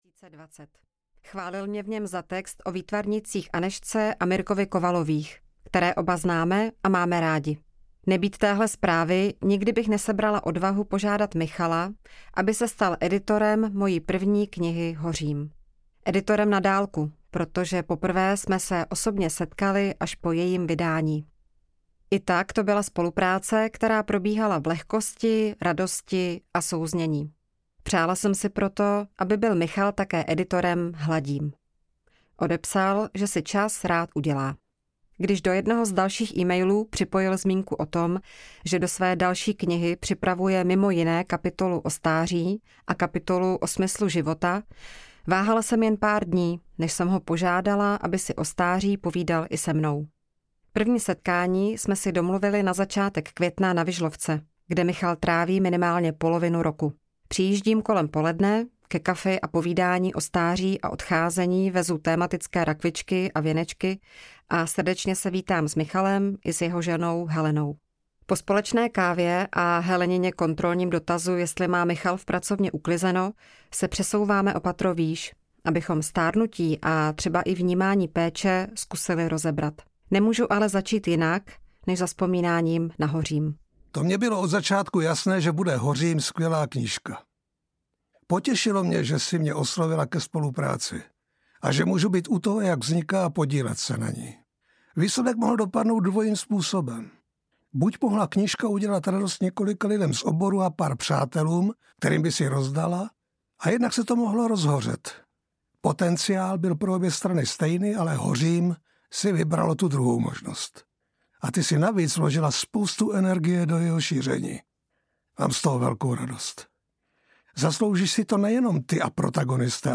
Hladím audiokniha
Ukázka z knihy
• InterpretMiroslav Krobot, Pavla Beretová, Jan Cina, Lenka Termerová, Petra Špalková